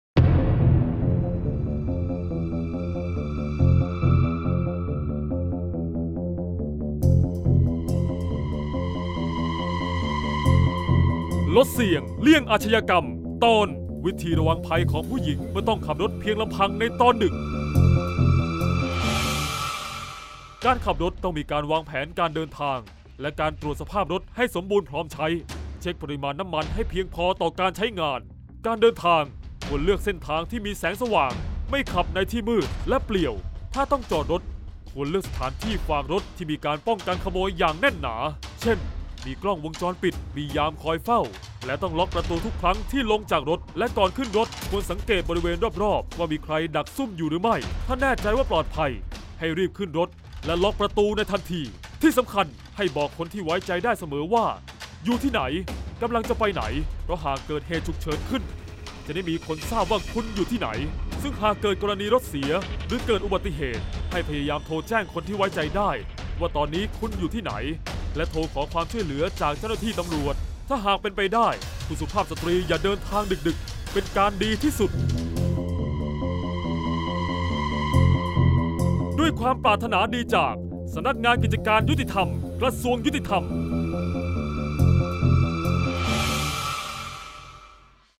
เสียงบรรยาย ลดเสี่ยงเลี่ยงอาชญากรรม 33-ระวังภัยผุ้หญิงขับรถดึก